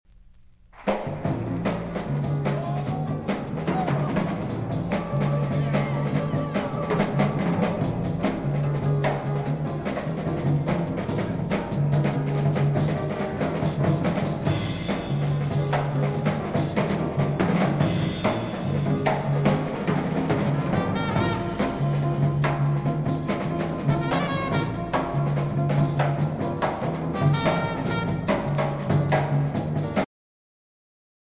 trumpet
bass trombone
alto saxophone
tenor saxophone
baritone saxophone
piano
percussion
Jazz vocals